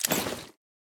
armor-open-2.ogg